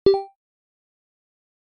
message_received.wav